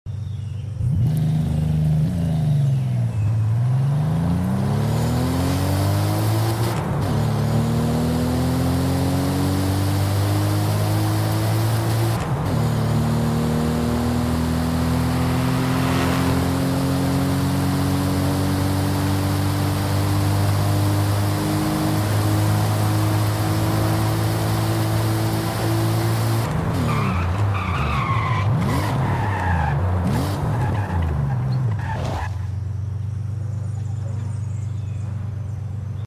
1991 Toyota Chaser GT Twin sound effects free download
1991 Toyota Chaser GT Twin Turbo Launch Control & Sound - Forza Horizon 5